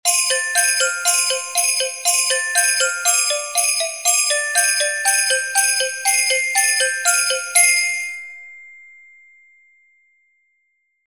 Jingle_Bells_Allegro_120.mp3